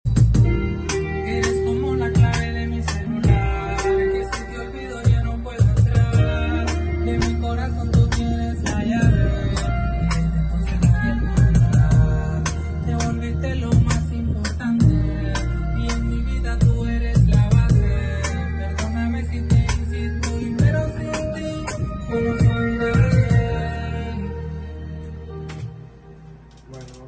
día de captura de guitarra sound effects free download